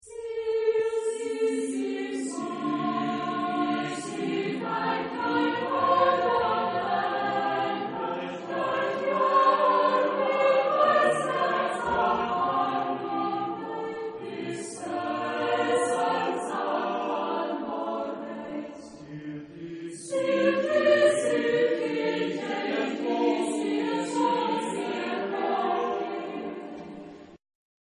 Genre-Style-Form: Madrigal ; Secular
Type of Choir: SATB  (4 mixed voices )
Tonality: D minor